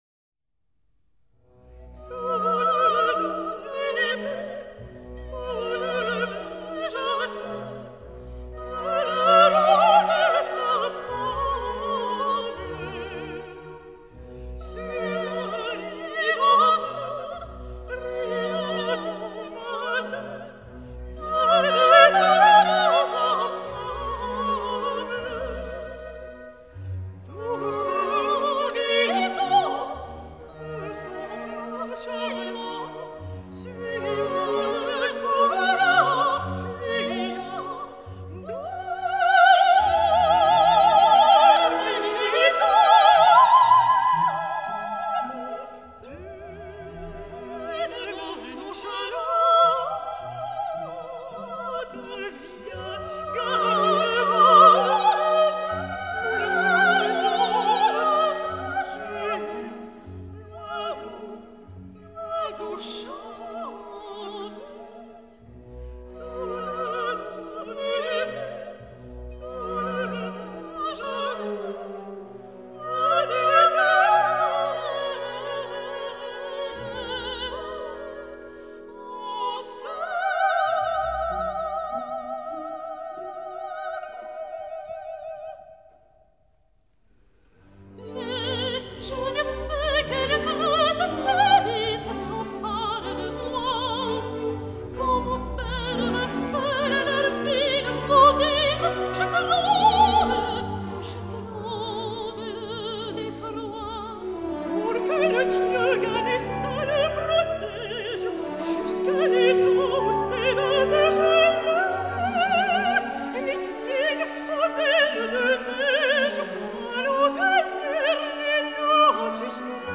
Quando arrivo la sera dopo l’università, mi chiudo nella mia stanza, col fresco del climatizzatore, faccio partire la musica, rigorosamente classica (la testa arriva già dolorante per lo studio) e… volteggerei per ore.
delibes_-_flower_duet.mp3